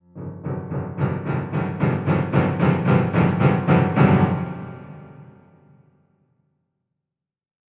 Music Bed; Low Dissonant Piano Clusters For Horror Effect.